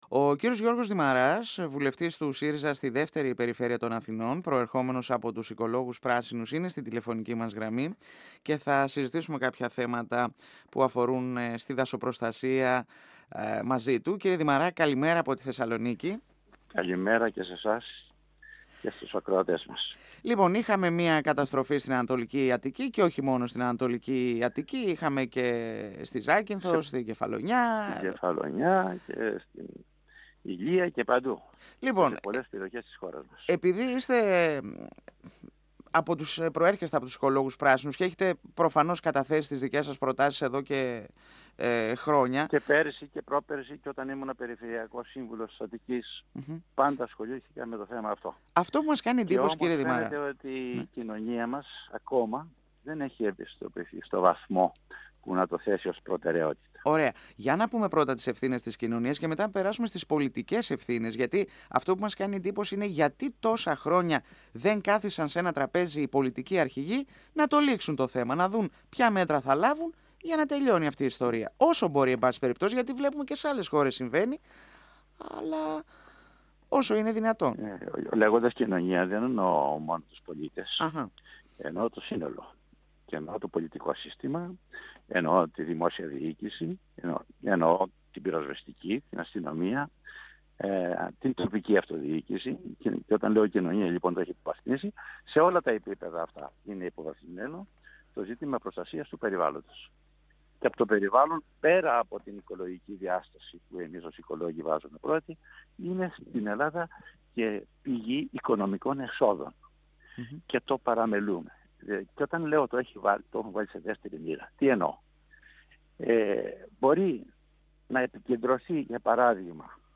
17Αυγ2017 – Ο βουλευτής Β΄ Αθηνών του ΣΥΡΙΖΑ Γιώργος Δημαράς στον 102 fm της ΕΡΤ3